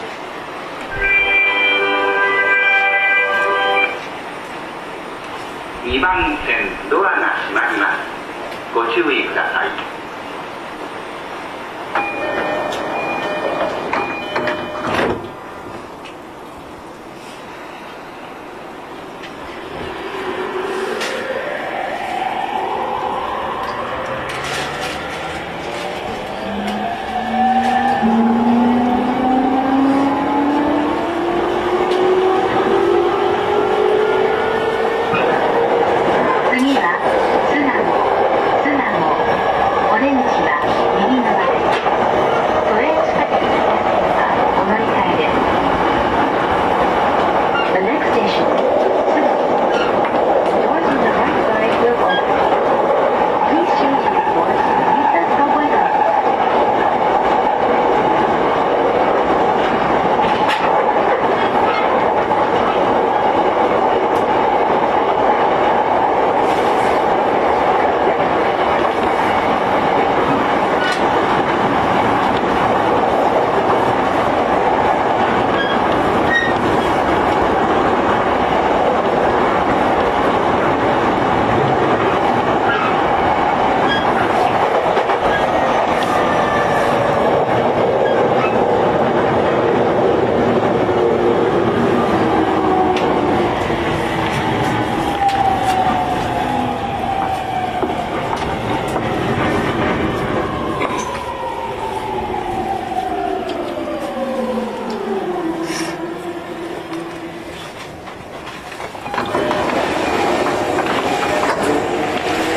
走行音
YM04 E231系 大塚-巣鴨 1：52 9/10 --